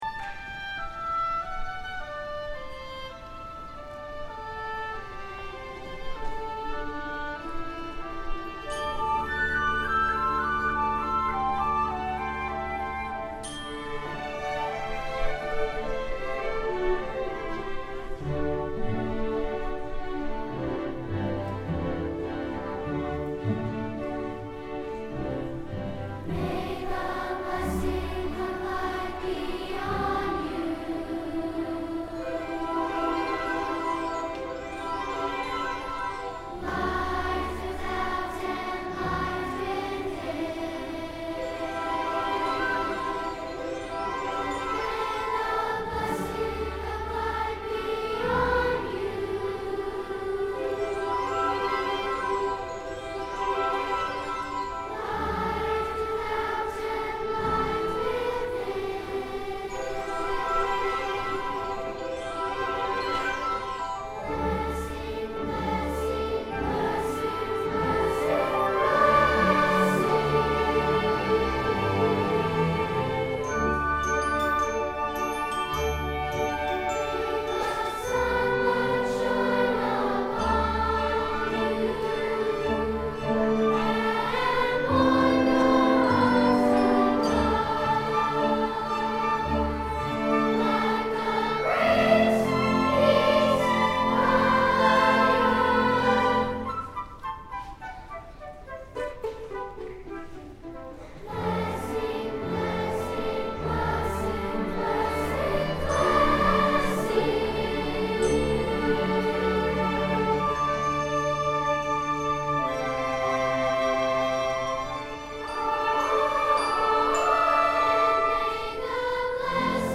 for Two-Part Treble Chorus and Orchestra (2010)